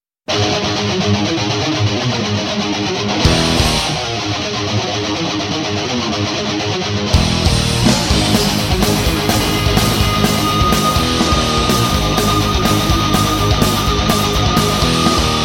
инстр.